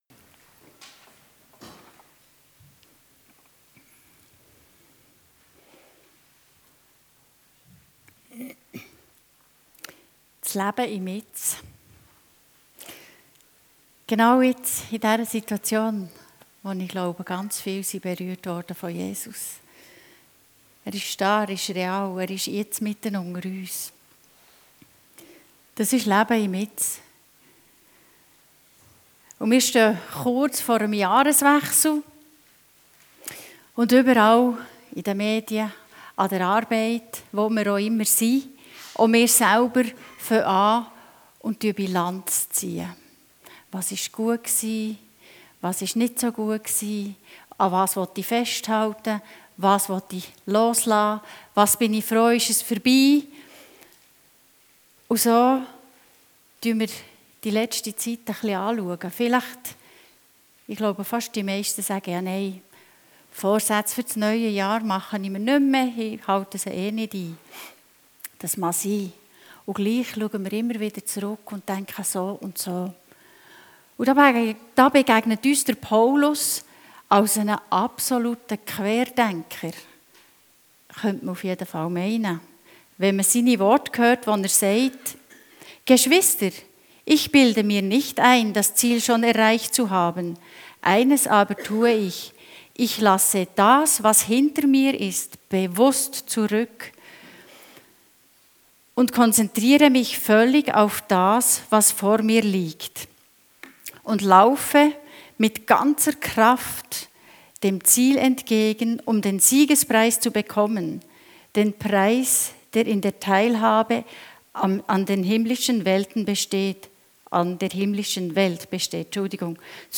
Dienstart: Gottesdienst